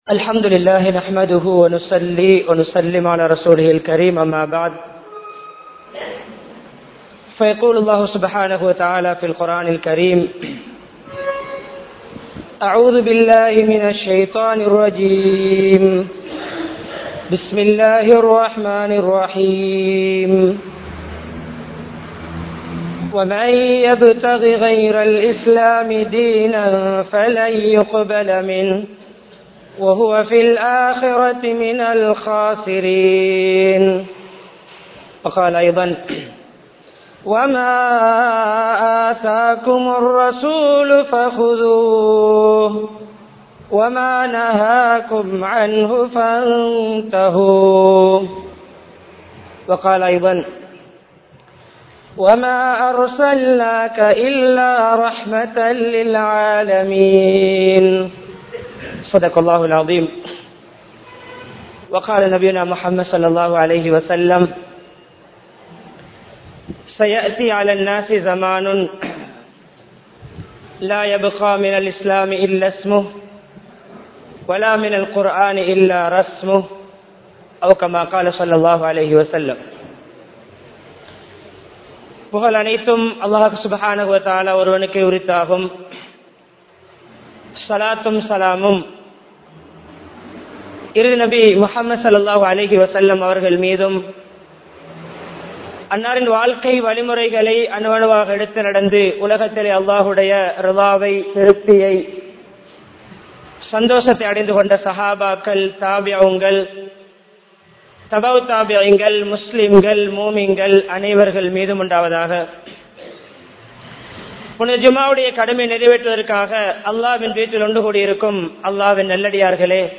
Pirachchinaihalukkaana Theervu (பிரச்சினைகளுக்கான தீர்வு) | Audio Bayans | All Ceylon Muslim Youth Community | Addalaichenai